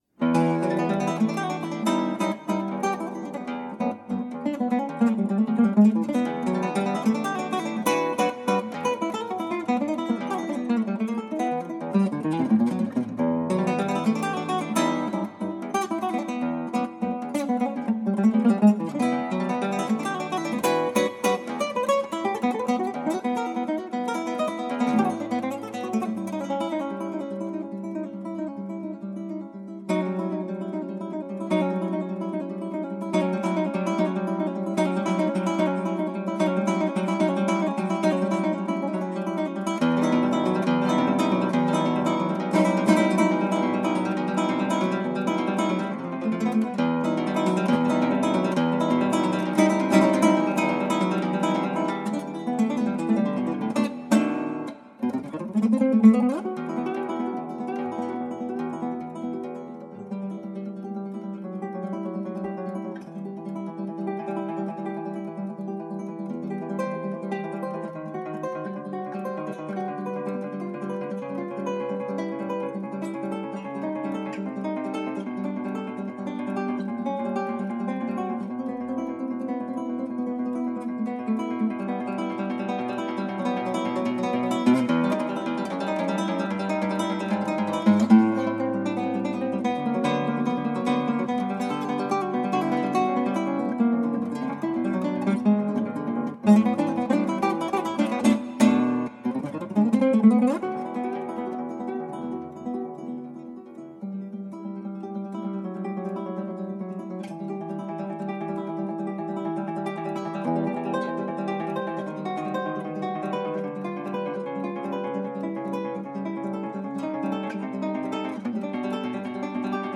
しかも現代曲 で早くパワーが必要でクラギ的には難曲ですね。
マイクは上と同じですが、DAWがCubaseとなりマイクプリ経由で直接DAWで録音していました。
一番の違いはエコーがCubaseのプラグインを使ったものとなったことでしょうか。
録音もへたくそで音が飽和している所もあるし・・・。
でも演奏は元気で良いですね、きっとこの時神様が下りてきたのでしょう。